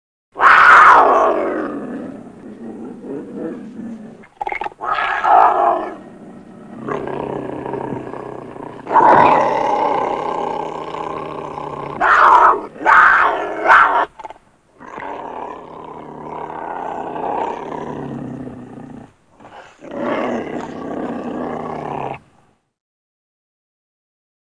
il feule ou gémit
Les cris du puma diffèrent selon les circonstances : ils peuvent être très aigus et ressembler à un sifflement en période de rut ; ils peuvent faire aussi penser à un fort ronronnement.
cougar.mp3